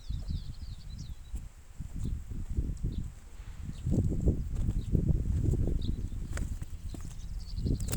Scientific name: Geositta rufipennis rufipennis
English Name: Rufous-banded Miner
Location or protected area: Ruta 307 entre El Infiernillo y Amaicha del Valle
Condition: Wild
Certainty: Recorded vocal